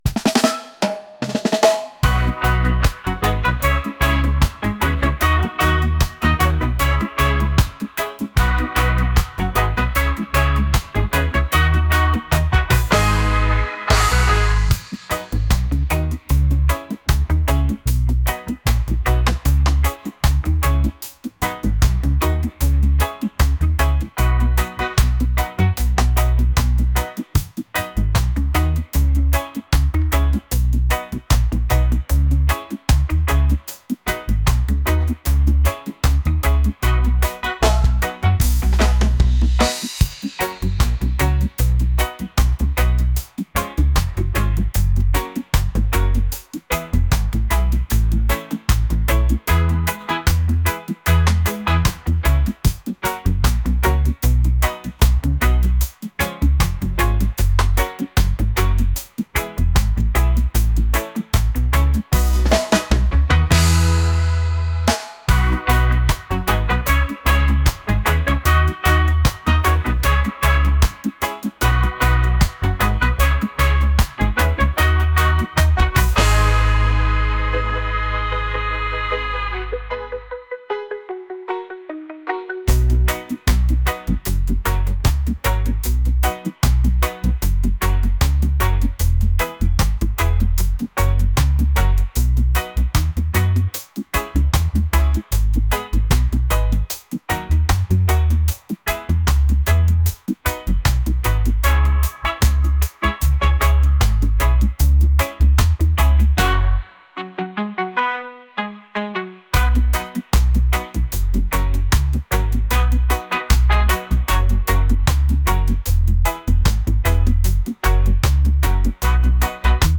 reggae | pop | lounge